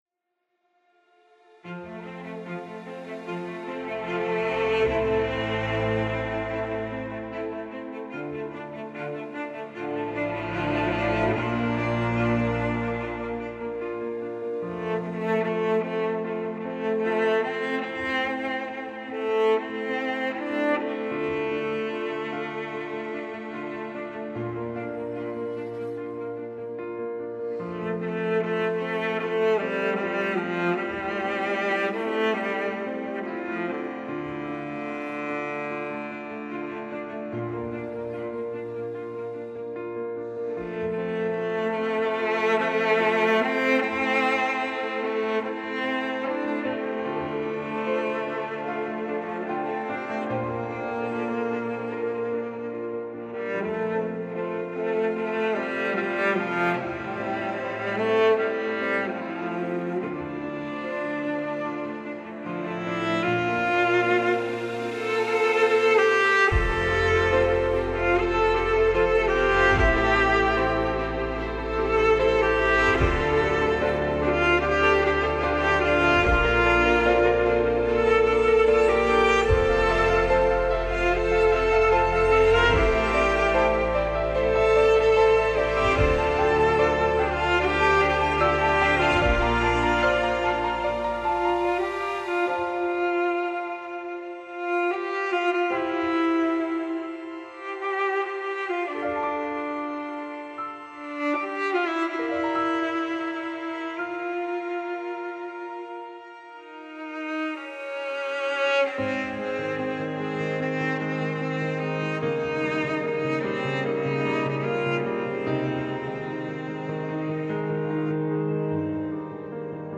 Classical Crossover
الهام‌بخش , امید‌بخش